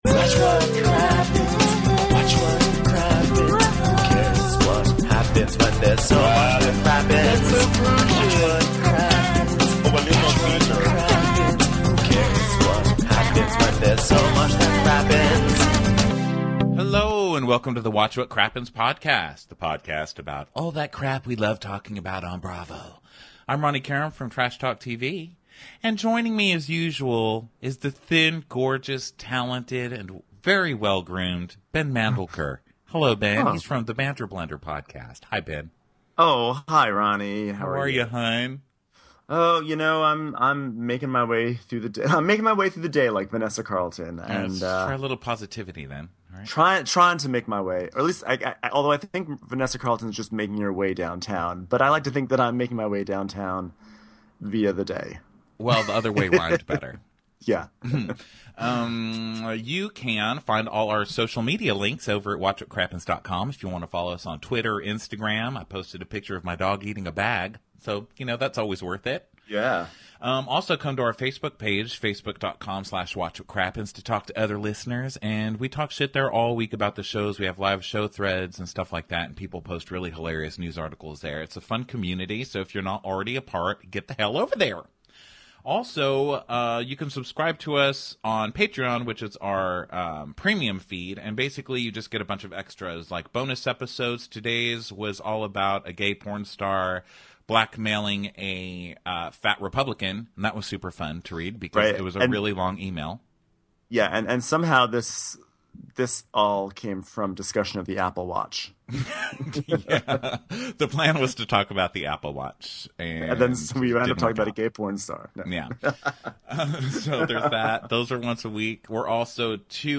We talk the return of Real Housewives of New York and then laugh our asses trying to imitate the Real Housewives of Melbourne before moving onto Real Housewives Atlanta getting Jesus to fix it and Blood Sweat and Heels? attempt to kill musical theater for good.